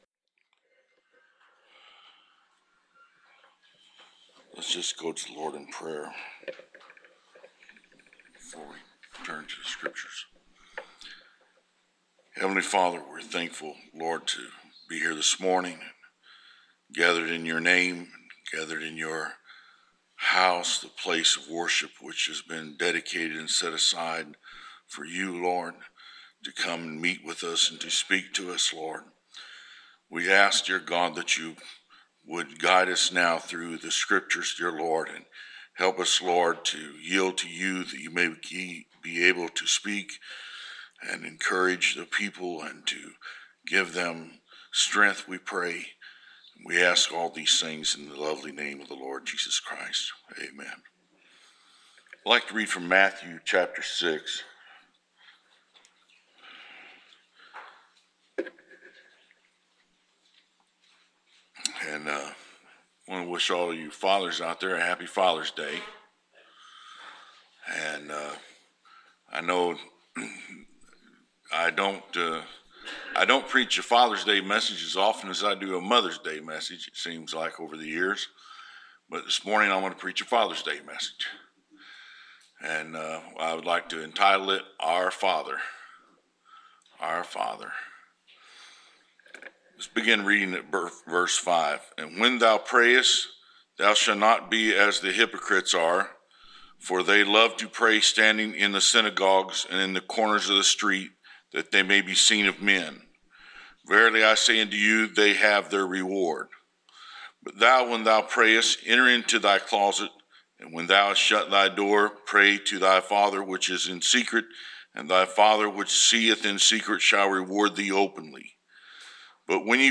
Preached June 20, 2021